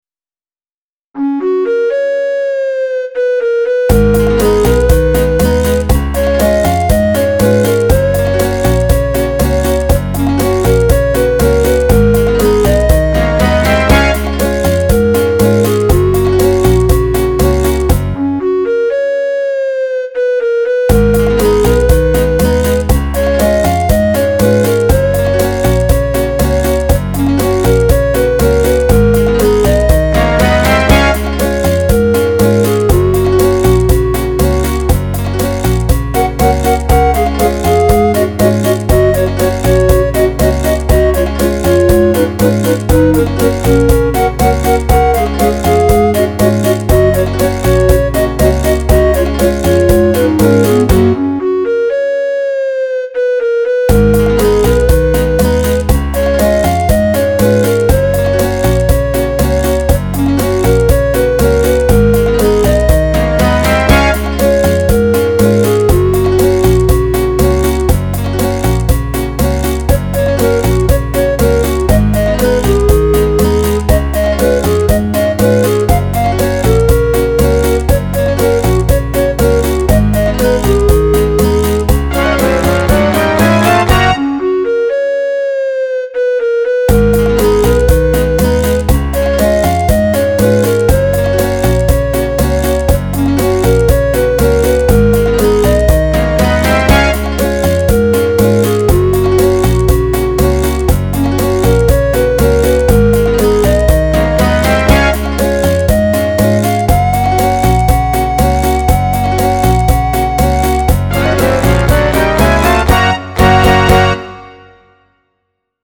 Make it a line dance cha-cha…